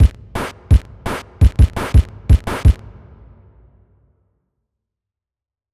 drums3.wav